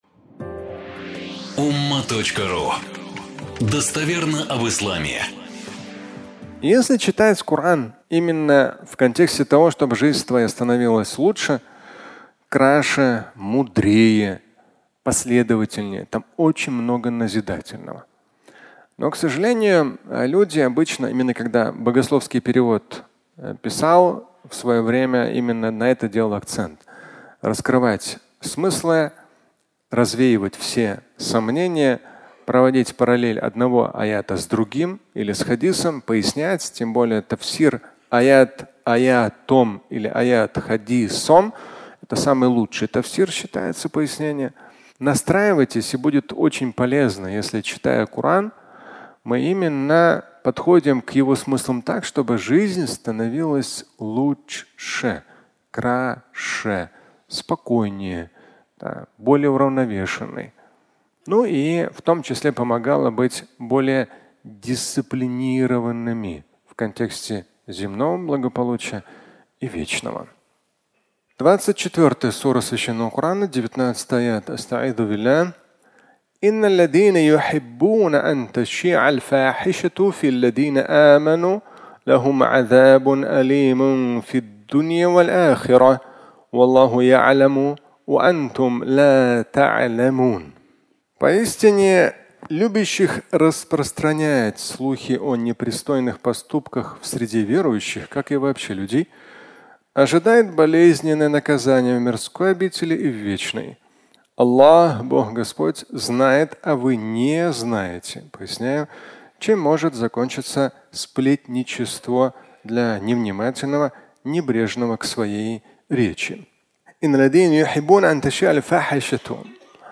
(аудиолекция)